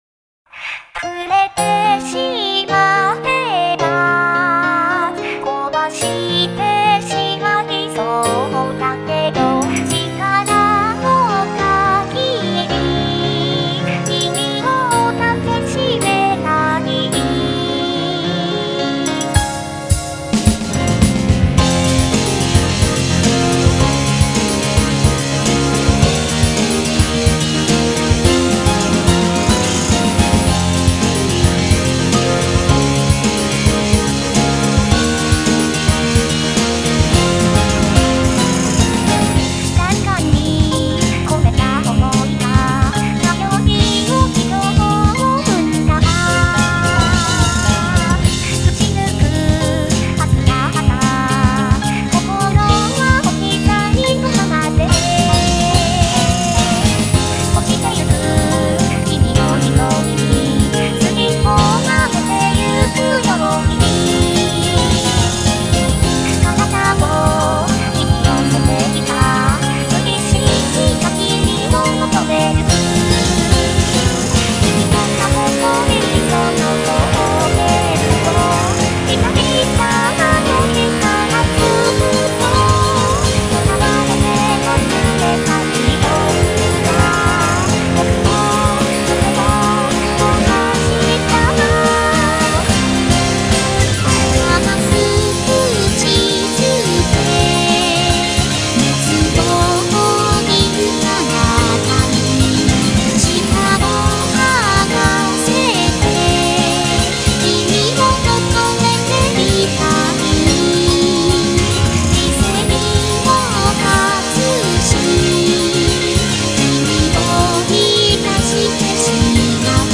ややエロティクな歌詞が特徴のロック曲です。
カッコいいサウンドの曲です。